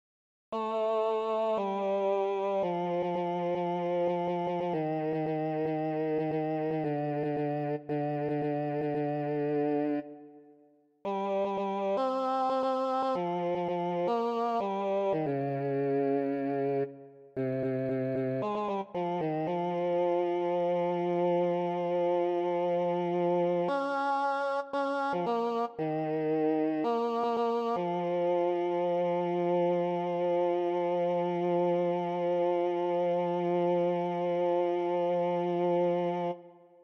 Key written in: F Major
Type: Barbershop
Each recording below is single part only.